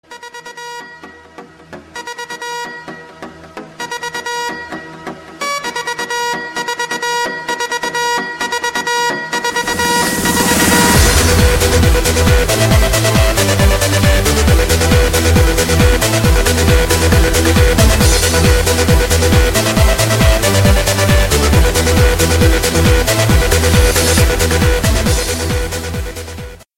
Categoría Alarmas